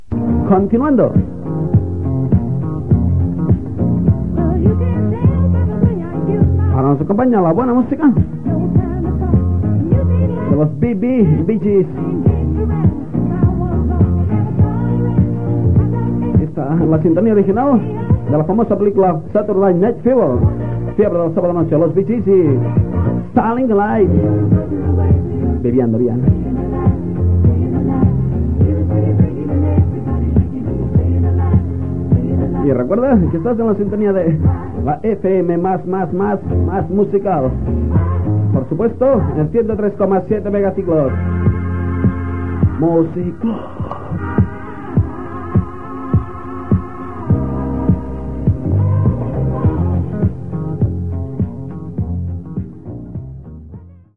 dff20c4b50a144bf25bb404c348548caa186dd05.mp3 Títol Music Club Emissora Music Club Titularitat Tercer sector Tercer sector Lliure Descripció Tema musical i identificació.